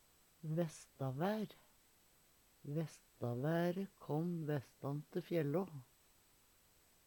vestavær - Numedalsmål (en-US)
vestavaer-v.mp3